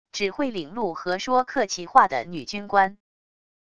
只会领路和说客气话的女军官wav音频